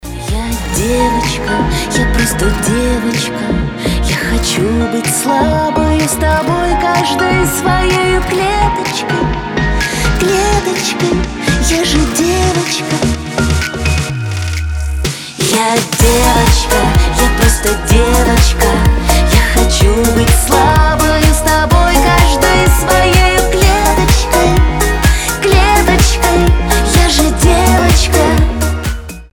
• Качество: 320, Stereo
поп